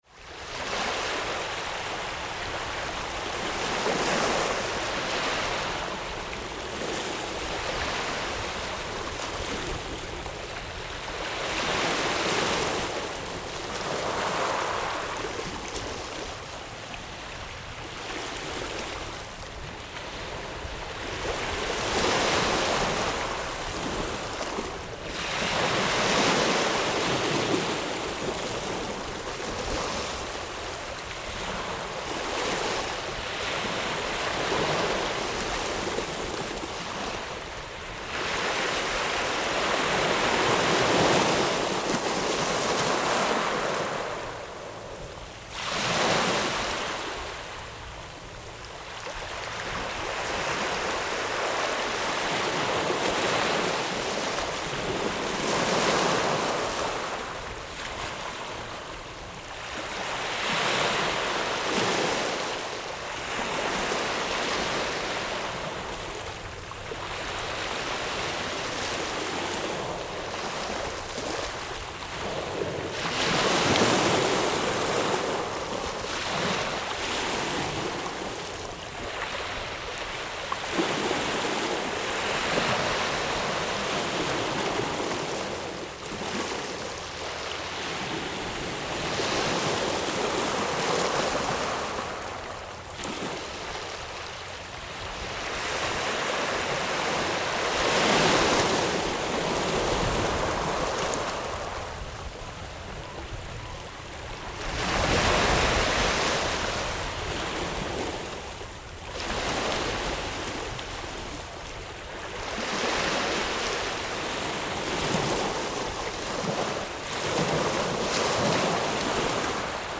Stone and driftwood beach
A recording of the tide coming in on a beach on the west coast of Canada (Sooke, British Columbia). This beach is pebbled right down to the waterline, so as waves receed an interesting sound of the pebbles being 'sucked' back into the water occurs.